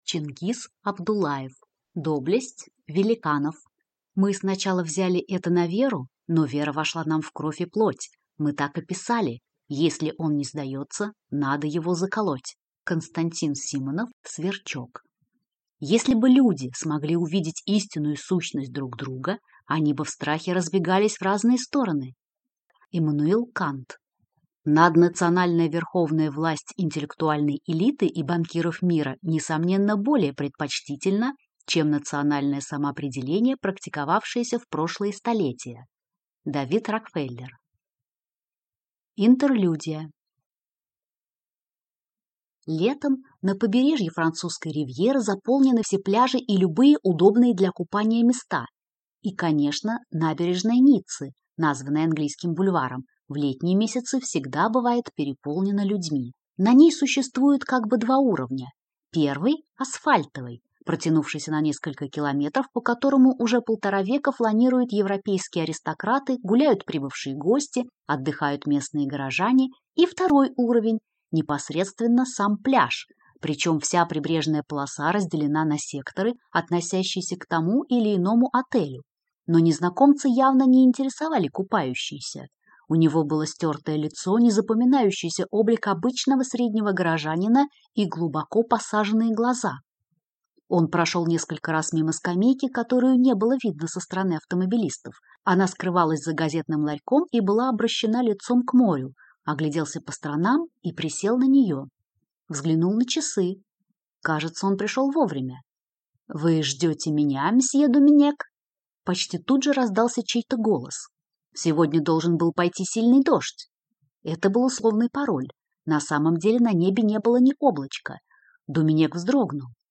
Аудиокнига Доблесть великанов | Библиотека аудиокниг
Прослушать и бесплатно скачать фрагмент аудиокниги